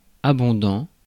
Abondant (French pronunciation: [abɔ̃dɑ̃]
Fr-abondant.ogg.mp3